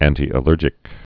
(ăntē-ə-lûrjĭk, ăntī-)